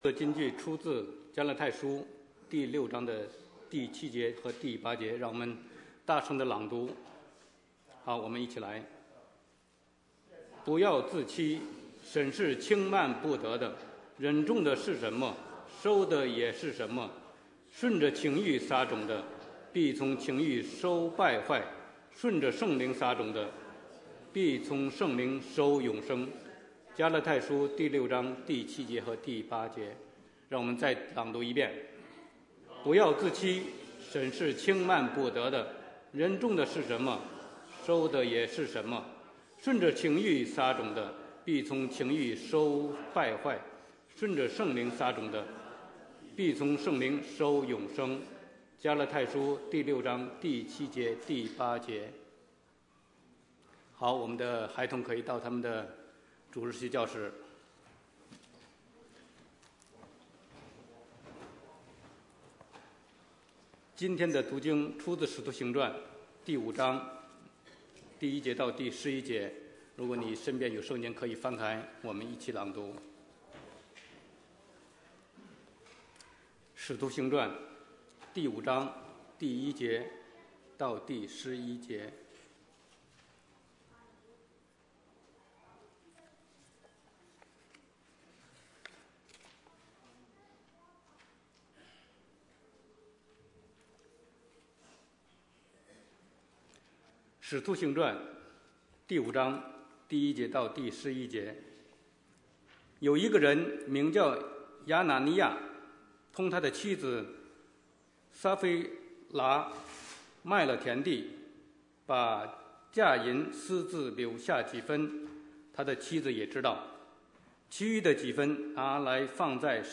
Bible Text: Acts 5:1-11 | Preacher